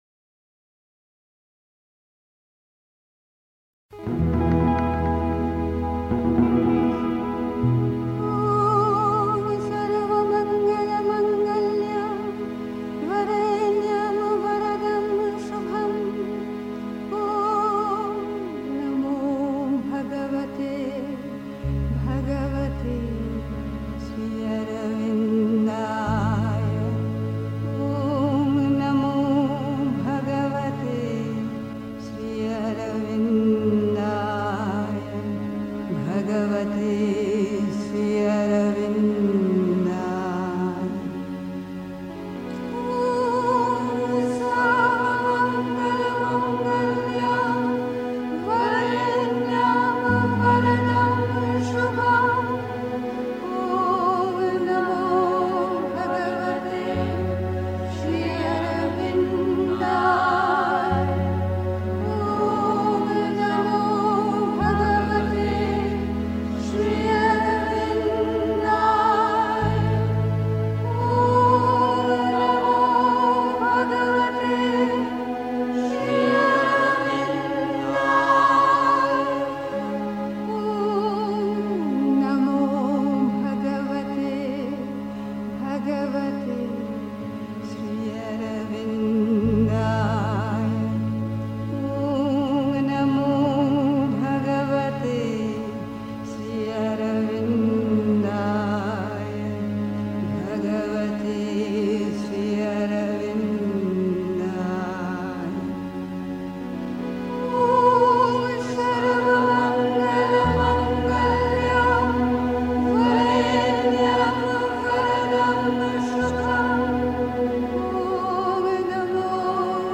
Pondicherry. 2. Das zentrale Geheimnis der Sadhana (Sri Aurobindo, CWSA, Vol. 32, p. 154) 3. Zwölf Minuten Stille.